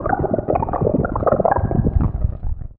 Index of /90_sSampleCDs/Best Service ProSamples vol.49 - FX-Area [AKAI] 1CD/Partition B/LIQUID-BUBBL